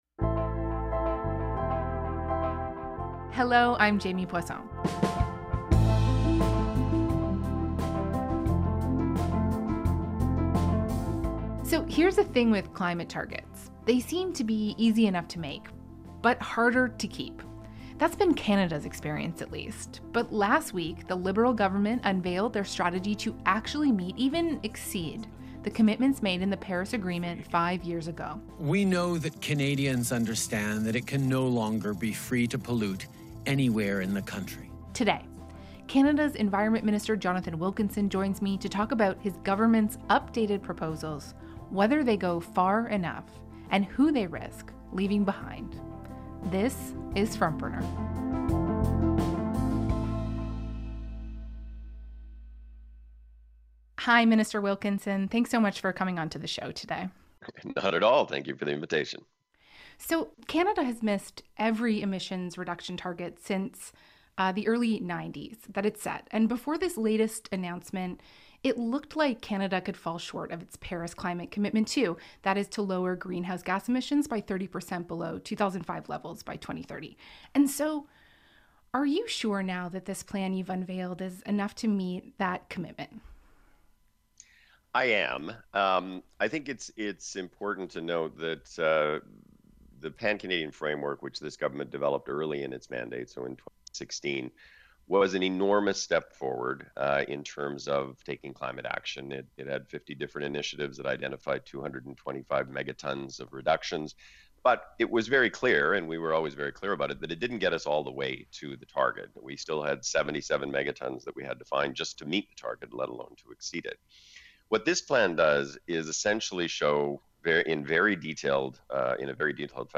A conversation with Canada’s environment minister